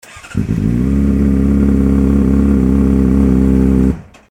モリワキマフラーのアイドリング音
アイドリング時の音圧は確実に純正を上回ってますね｡